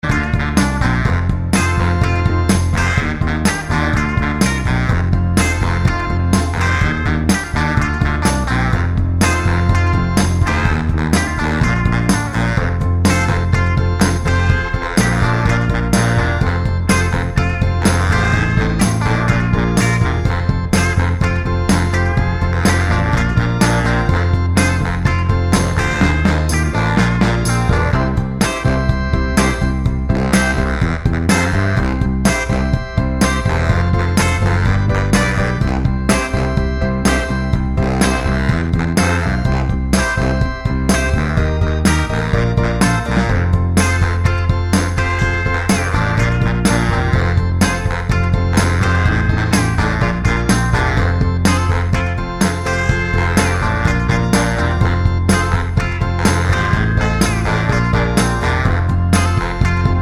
Minus Lead Sax Soul